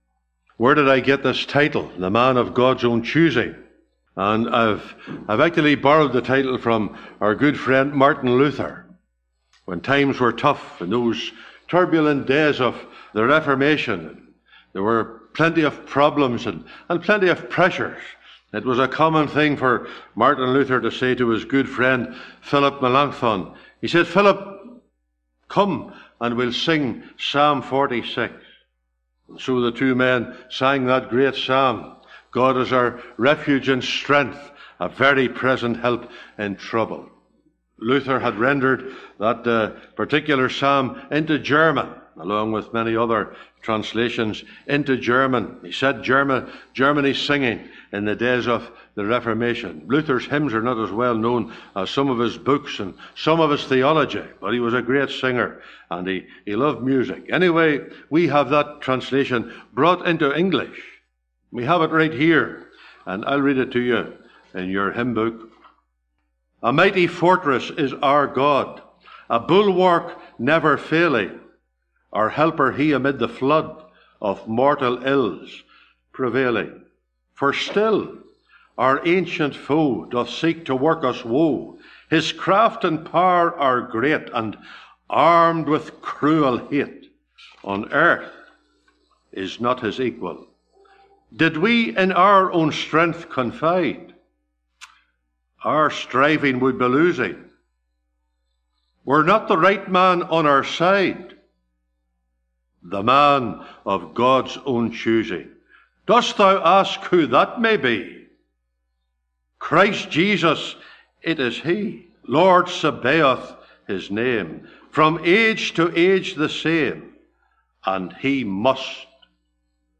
(Recorded in Stark Road Gospel Hall, 9th Sept 2024)